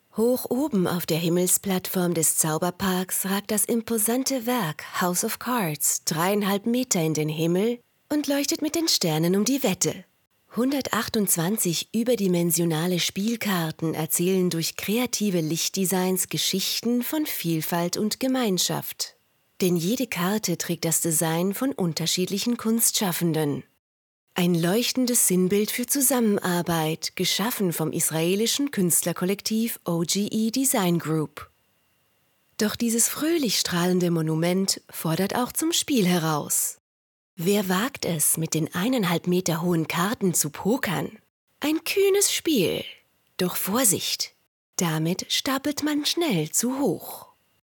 Audiodeskription